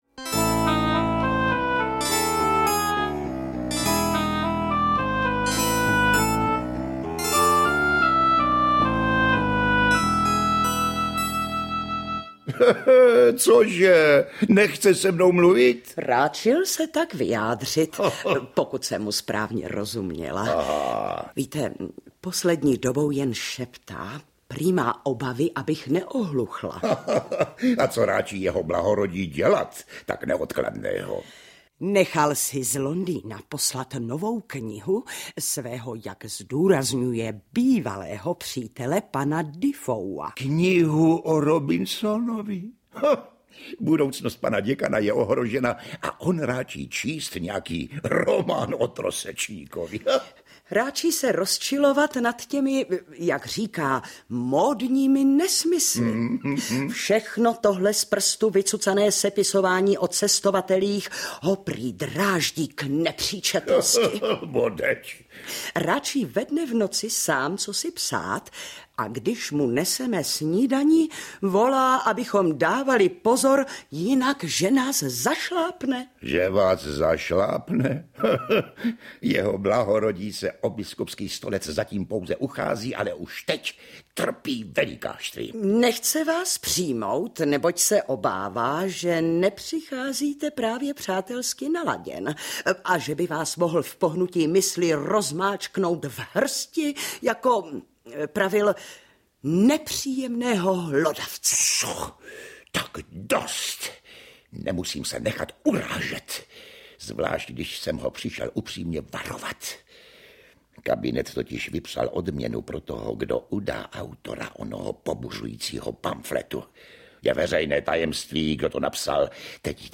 Interpreti:  Oldřich Kaiser, Jiří Lábus, Otto Lackovič, Josef Somr, Valerie Zawadská
AudioKniha ke stažení, 2 x mp3, délka 1 hod. 52 min., velikost 102,5 MB, česky